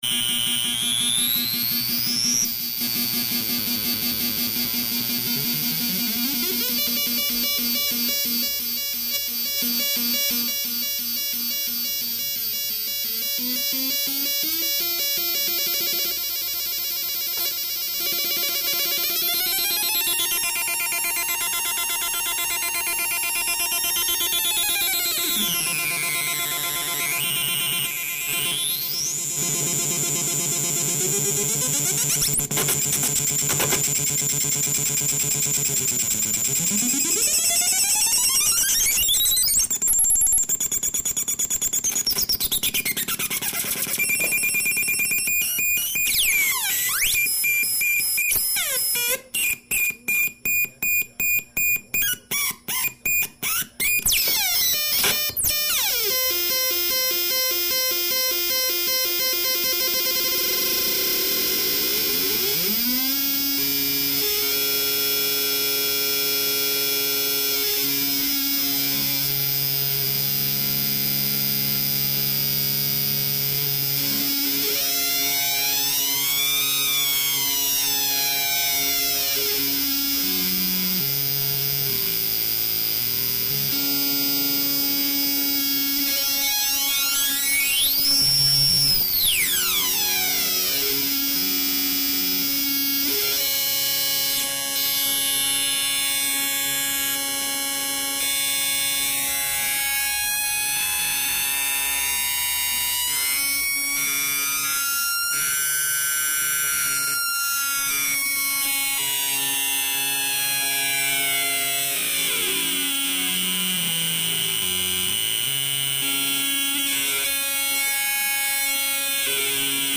2nd Test with NAND Gate Synth 4 Osc